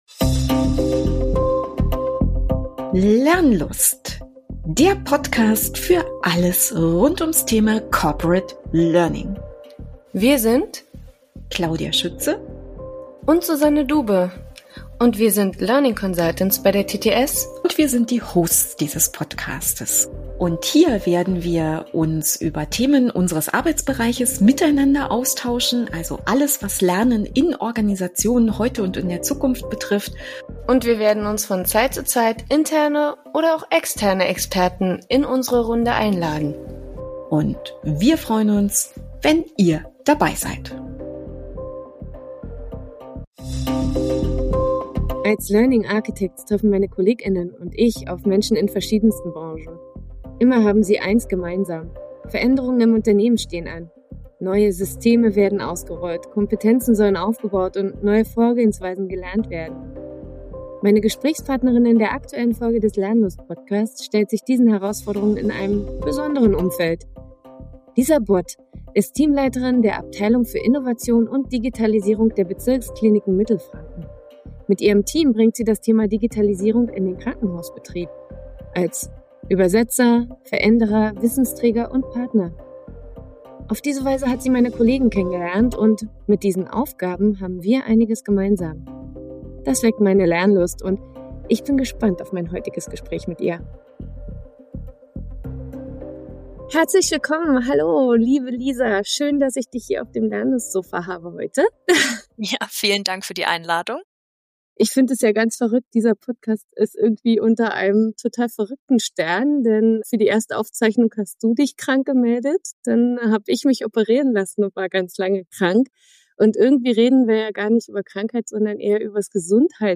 Krankheit hat die beiden Sprecherinnen aufgehalten und um Gesundheit geht es in dieser Folge. Tatsächlich sprechen beide über das Thema Digitalisierung im Gesundheitswesen.